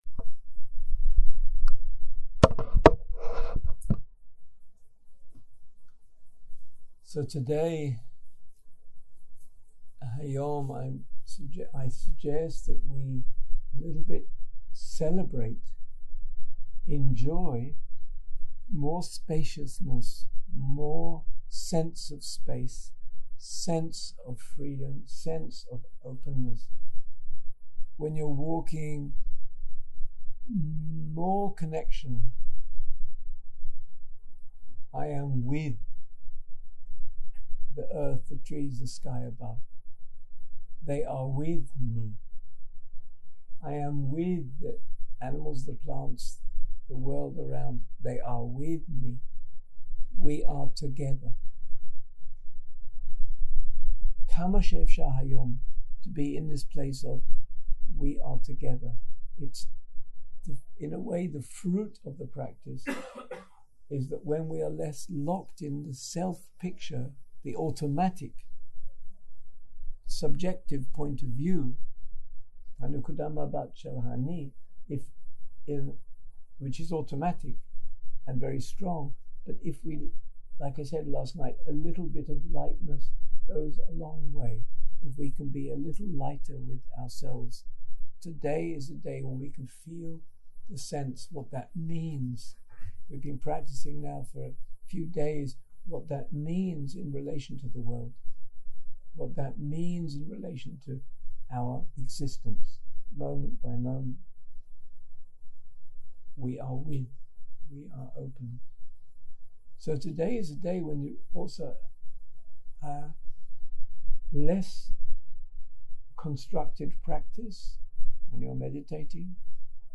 Guided meditation